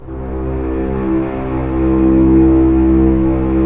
Index of /90_sSampleCDs/Roland - String Master Series/STR_Cbs Arco/STR_Cbs2 Orchest